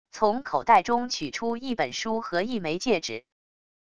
从口袋中取出一本书和一枚戒指wav音频